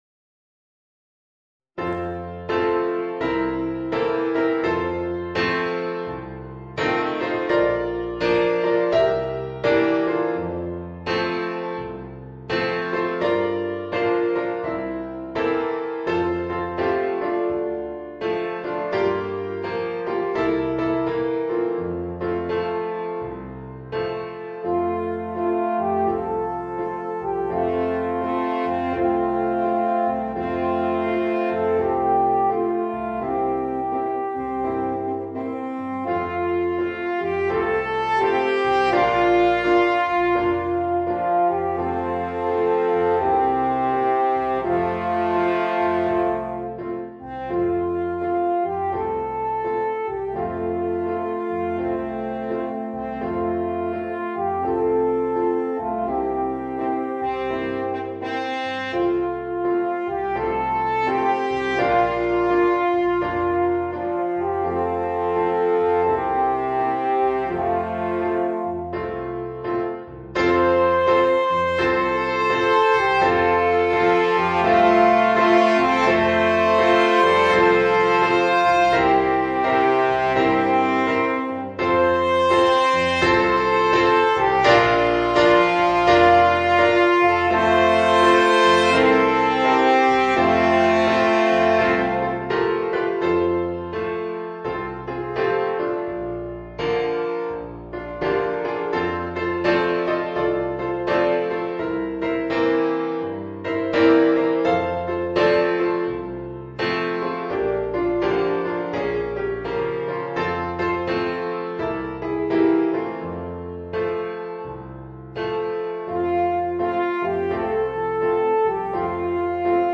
für 3 Alphörner in F und Klavier Schwierigkeit